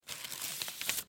Звуки шуршания бумагой
Вытягиваем листок из бумажной стопки (звук)